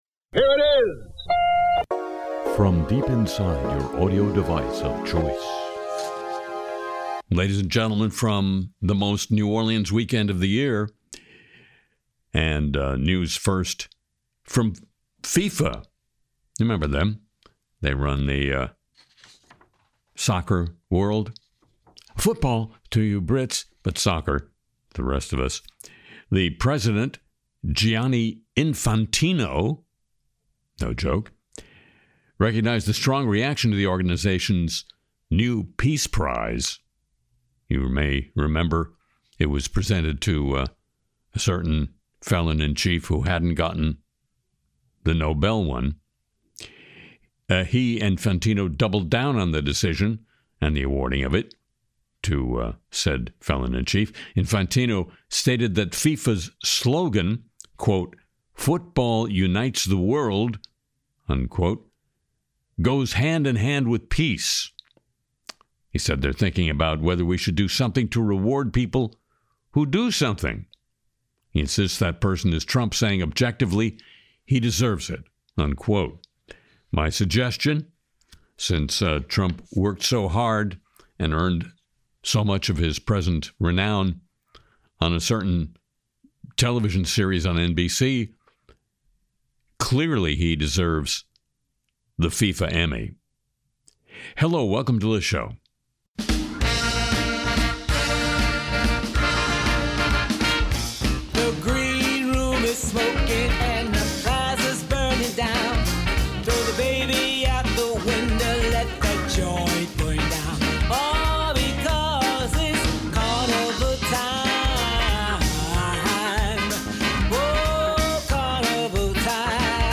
Harry sings “Just Begin Again,” lampoons Trump’s Truth Social Audio, covers Epstein Files news, and explores AI entering operating rooms and churches.